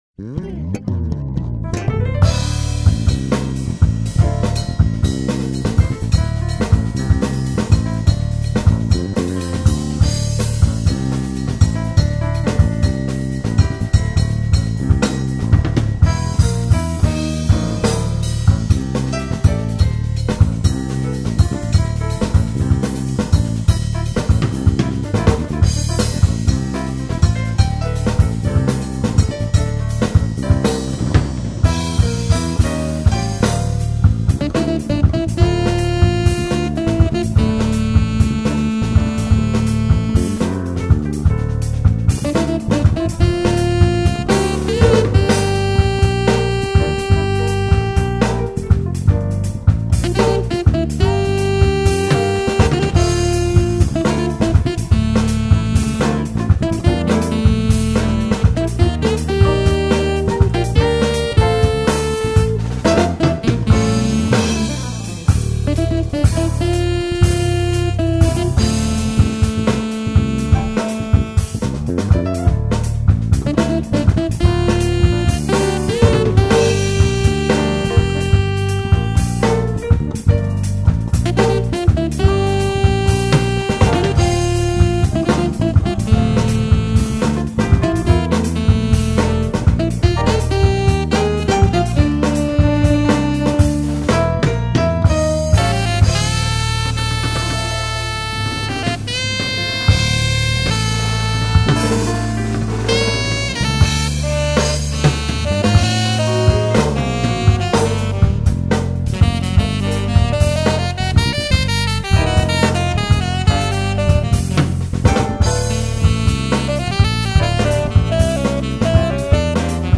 2464   08:26:00   Faixa: 7    Jazz